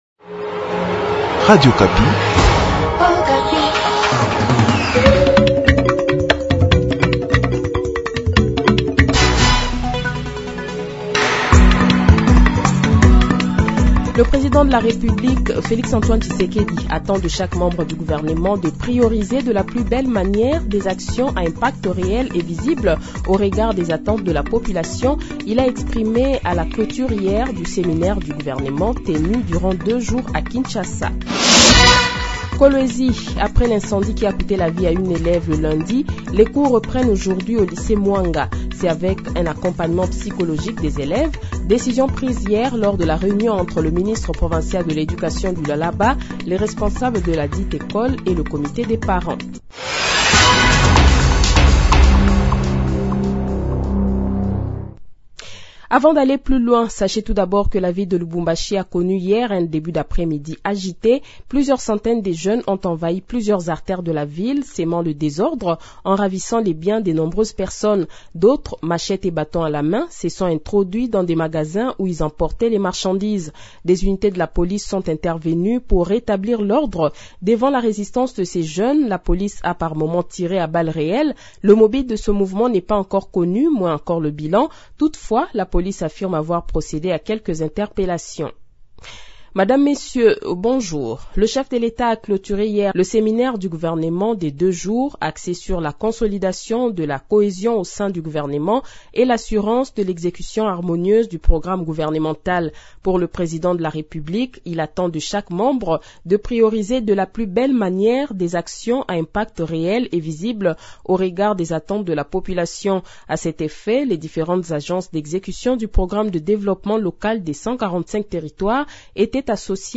Le Journal de 7h, 27 Avril 2023 :